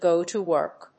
アクセントgò to wórk